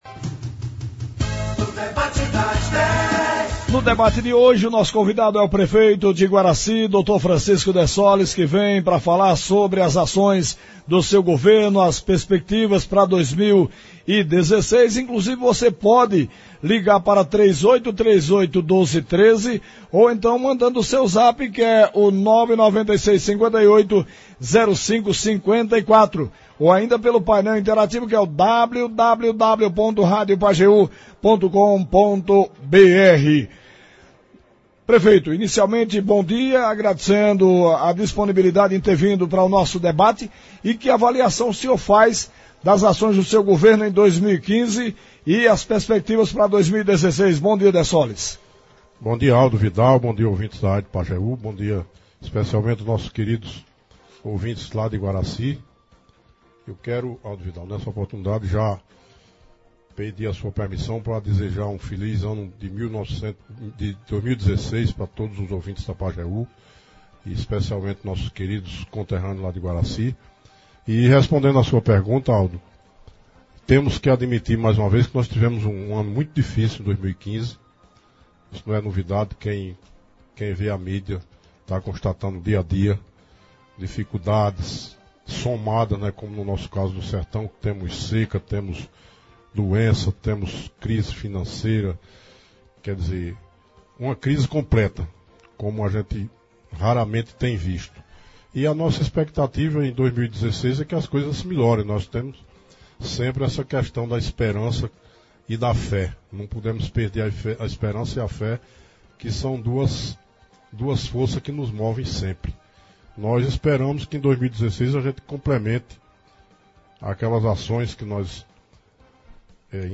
Hoje no Debate das Dez da Rádio Pajeú, o prefeito de Iguaracy Francisco Dessoles fez uma avaliação do ano de 2015, falou das dificuldades enfrentadas e disse o que espera para 2016.